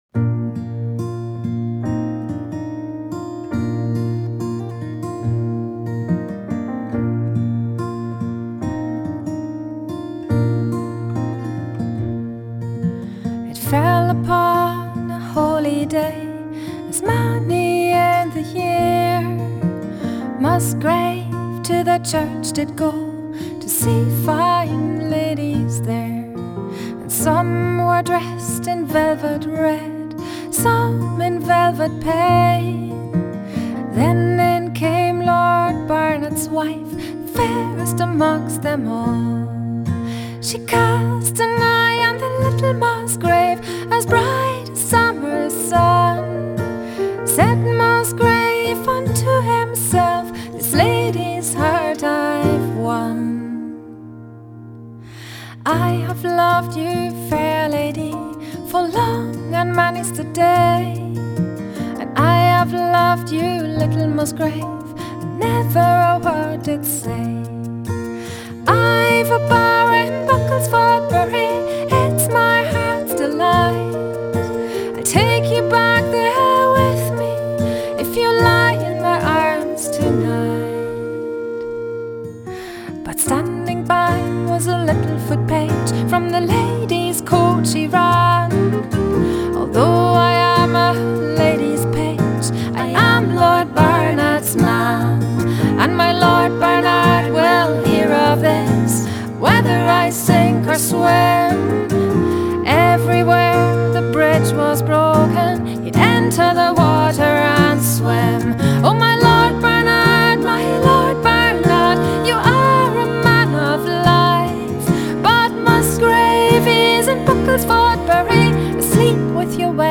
Genre: World, Folk, Celtic, Contemporary Celtic